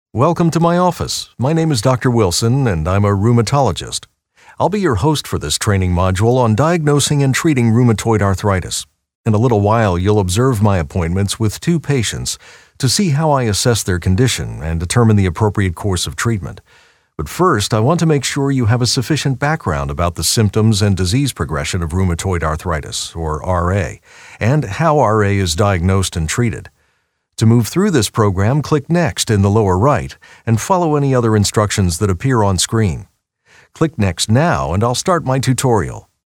Veteran VO talent with warm, authoritative, clear and convincing voice.
eLearning
My voice is best described as warm, articulate, friendly and authoritative.